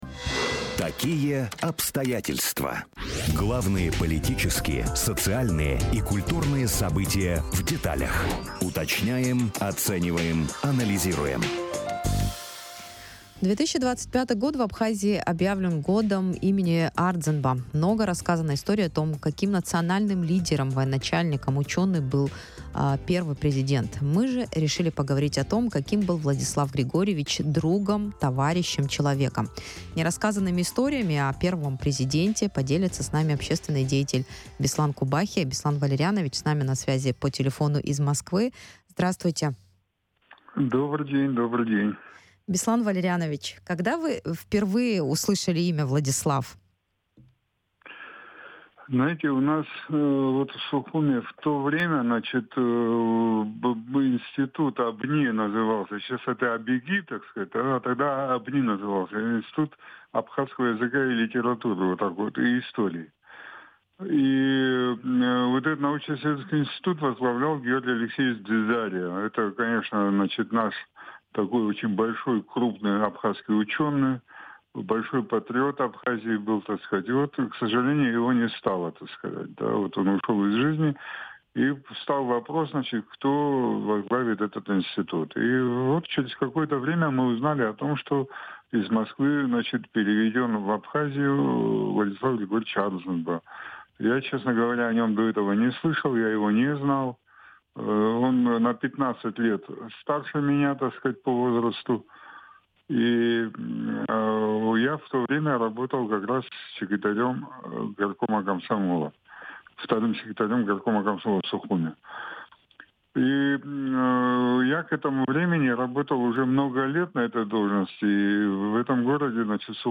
В интервью на радио Sputnik – о том, каким был Ардзинба другом, товарищем...